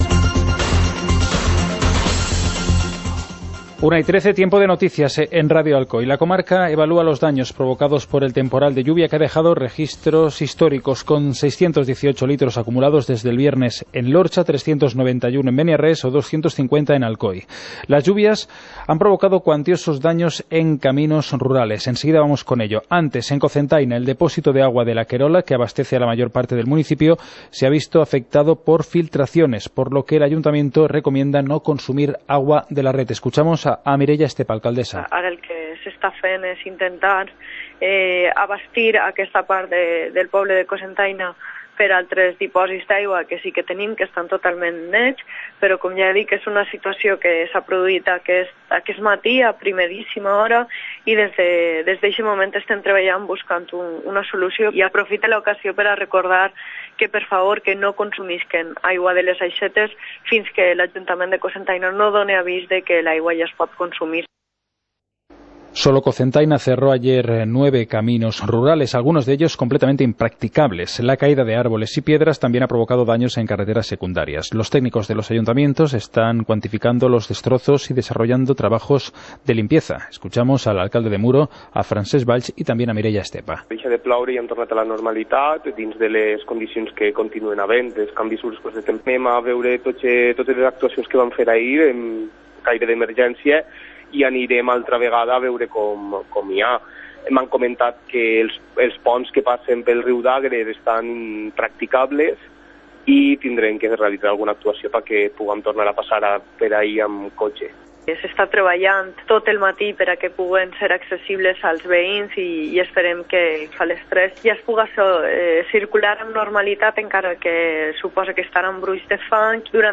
ÀUDIO Informatiu comarcal (20/12/2016) Ràdio Alcoi (mp3) | Enllaç a la notícia